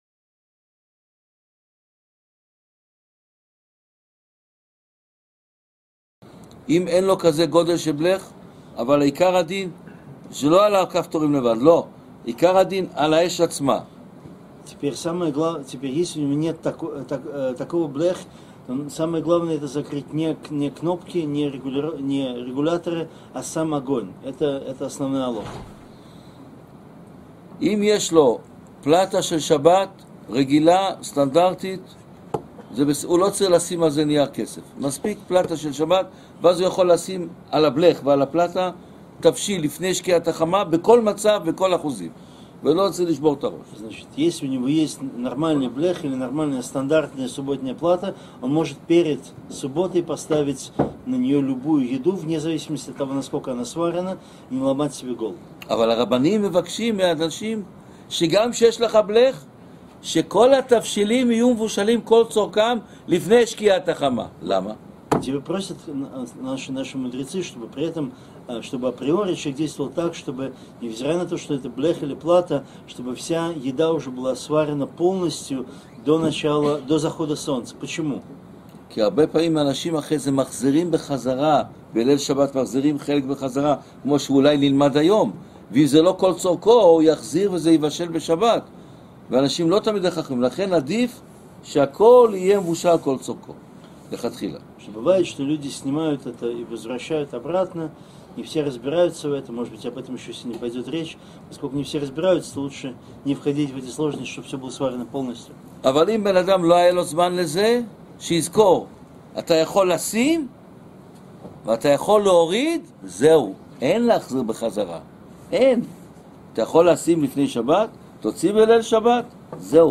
на семинаре Беерот Ицхак в Цюрихе
с переводом на русский!